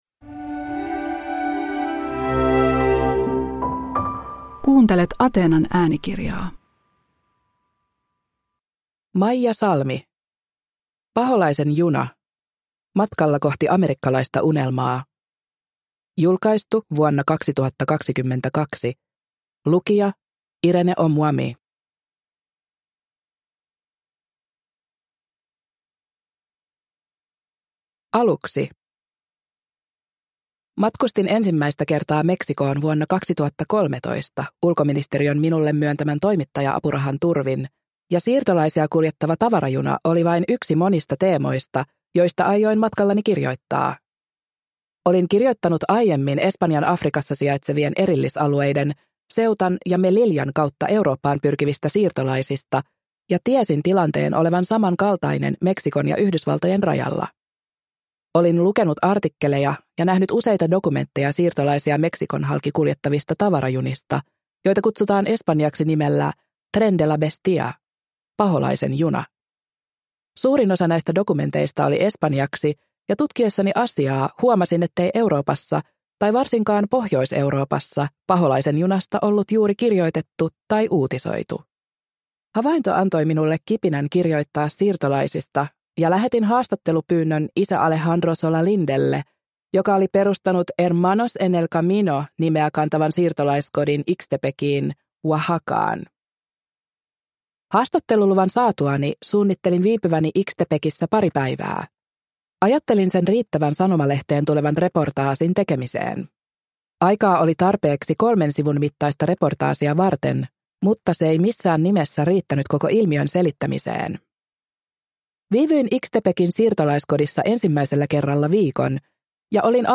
Paholaisen juna – Ljudbok – Laddas ner